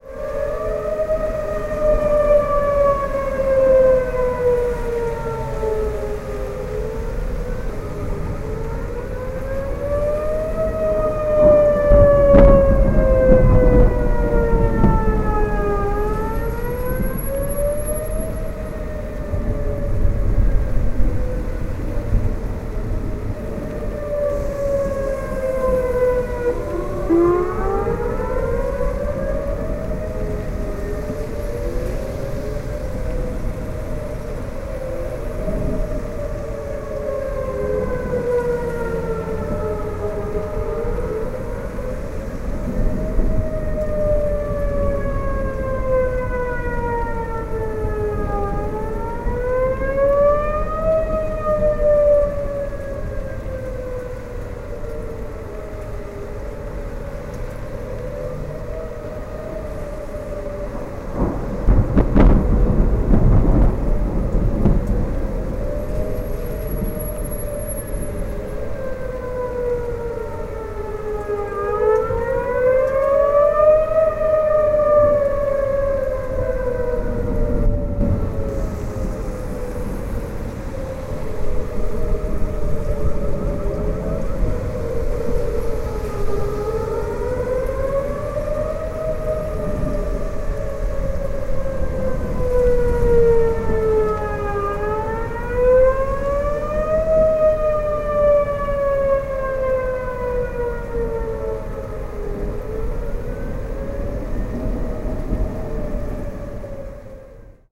In the urban meadow east of the mountains
tornado sirens blare a sinister drone, so naturally I get thisshow going with a drone by Zomes.
siren.mp3